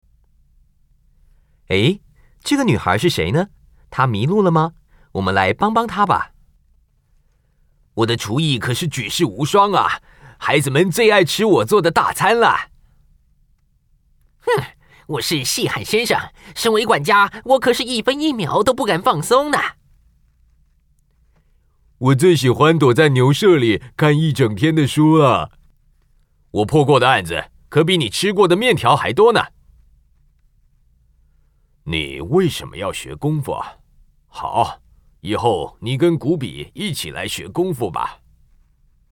台語配音 國語配音 男性配音員
他擁有多變且廣闊的音域，從青春洋溢的少年代角色到沉穩成熟的中年人物皆能詮釋自如。